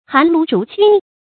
韓盧逐逡的讀法